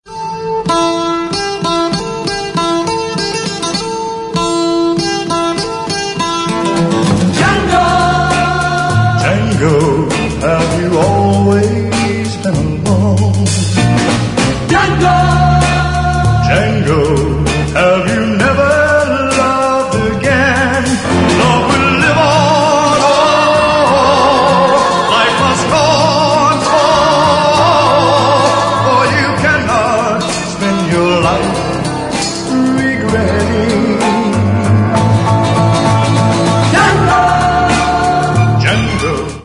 Cine y Televisión